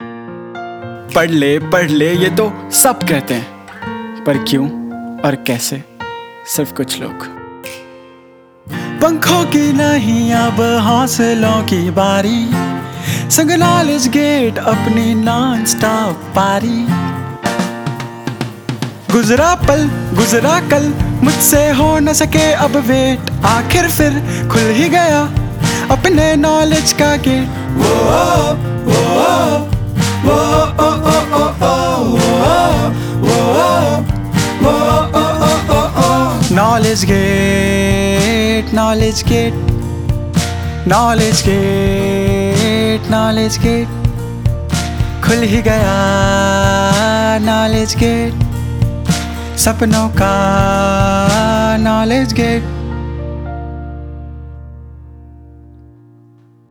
Client Song Example
• Ad Jingles